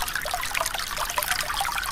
draugnorak/sounds/terrain/stream.ogg at master